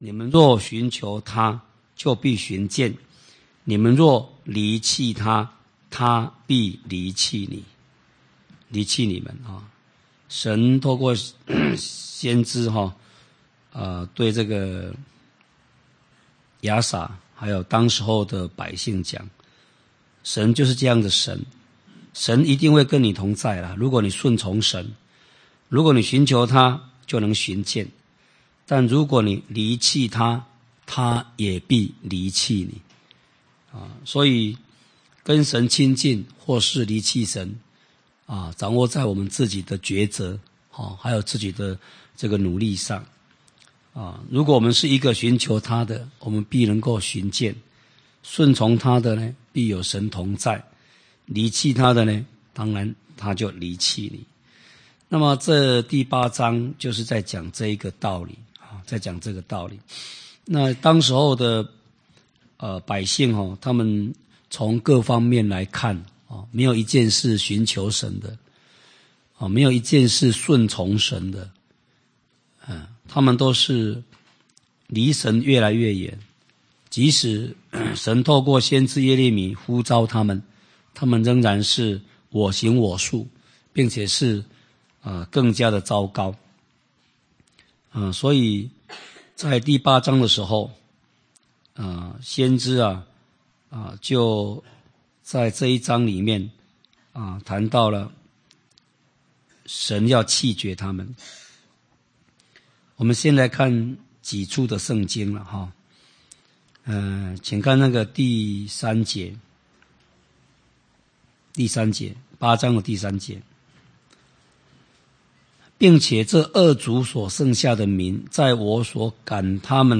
講習會
地點 台灣總會 日期 02/17/2011 檔案下載 列印本頁 分享好友 意見反應 Series more » • 耶利米書44-01：緒論(1